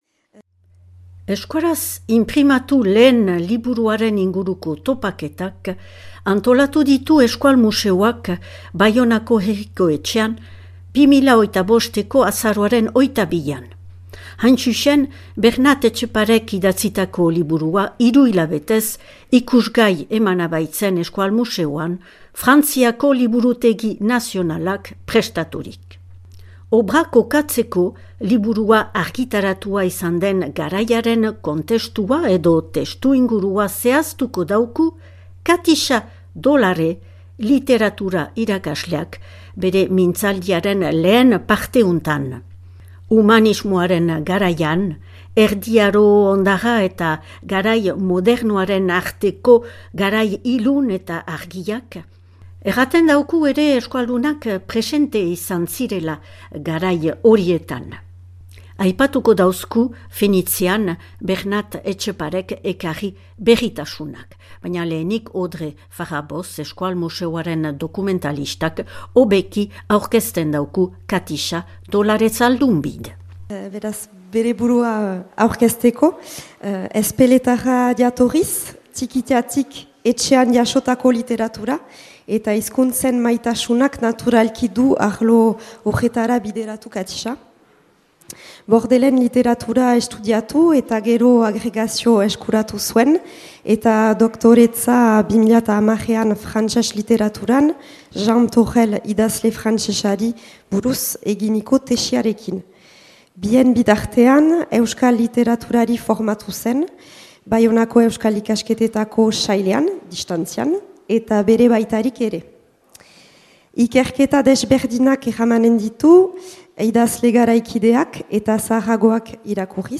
Euskaraz inprimatu lehen liburuaren inguruko topaketak, Euskal museoak antolaturik 2025eko azaroaren 22an Baionako Herriko Etxean.